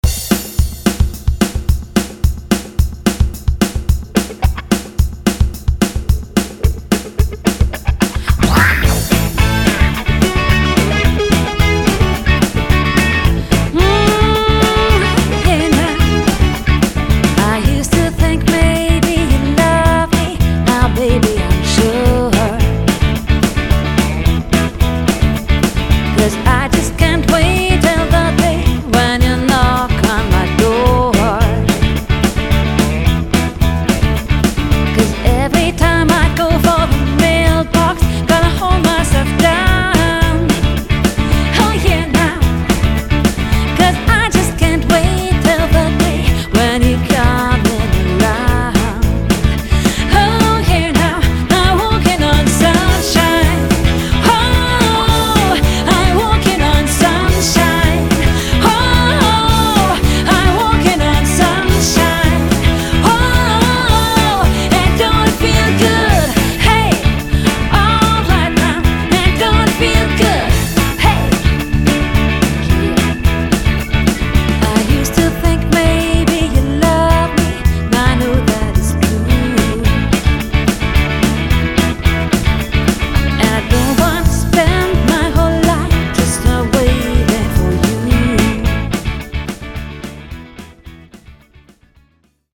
Trio oder Quartett – Sängerin u. Sänger